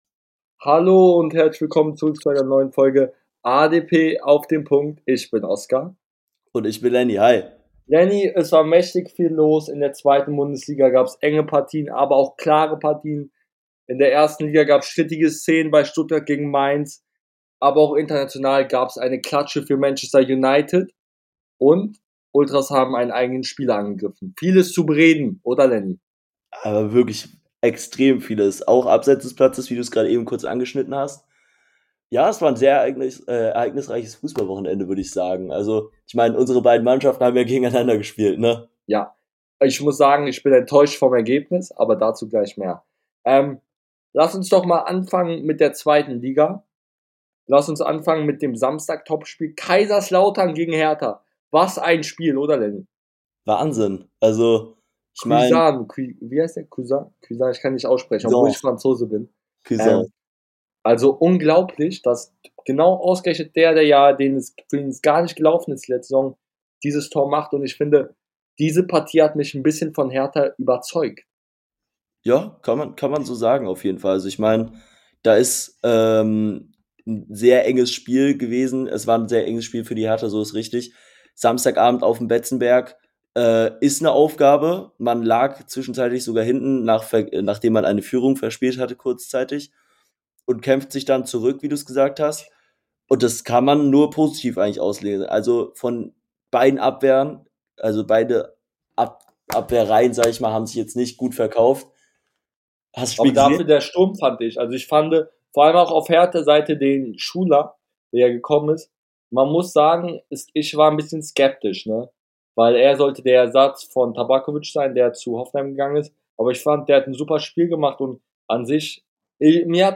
In der heutigen Folge reden die beiden Hosts über den ersten Trainerwechel in Darmstadt , den Bundesligaspieltag , den Angriff der Nürnbergultras und vieles mehr